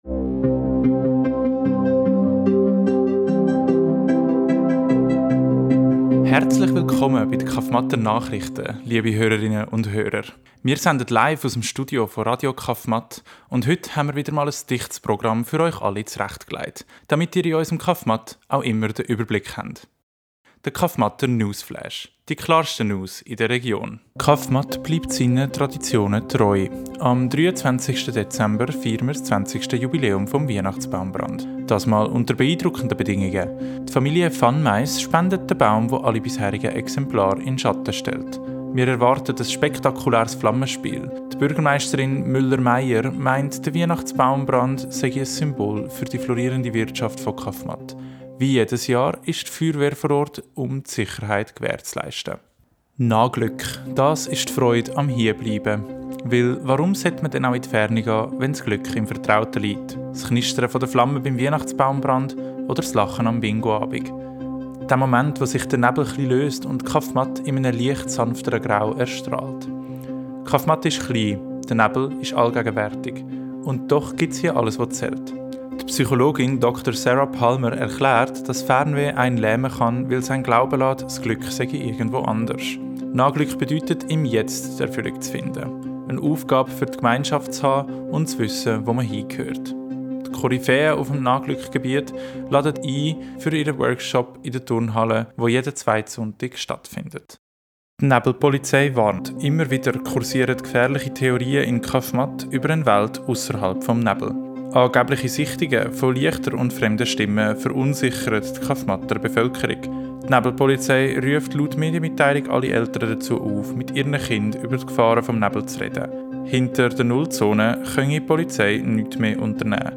In diesem Podcast werdet ihr nach Kaffmatt mitgenommen, das Dorf, das seit Jahren von undurchdringlichem Nebel umhüllt wird. Wir hören die Nachrichten des Regionalradios von Kaffmatt, gesprochen und erfunden von den Beteiligten des Stücks.